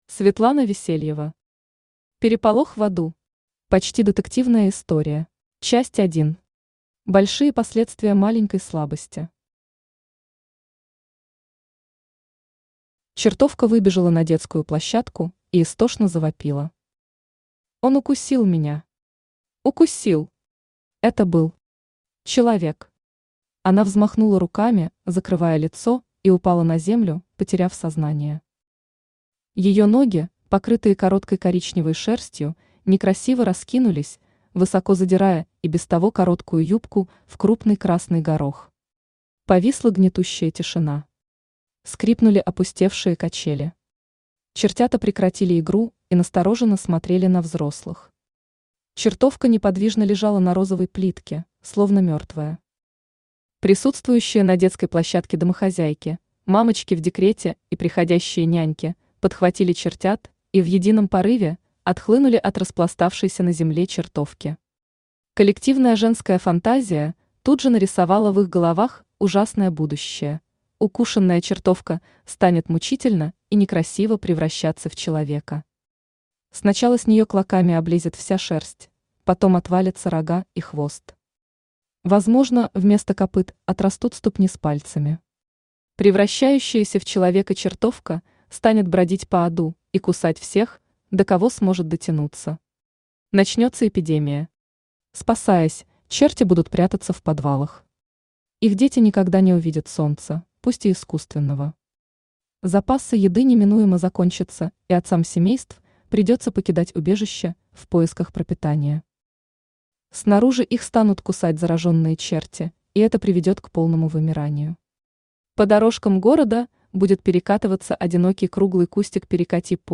Аудиокнига Переполох в Аду. Почти детективная история | Библиотека аудиокниг
Почти детективная история Автор Светлана Весельева Читает аудиокнигу Авточтец ЛитРес.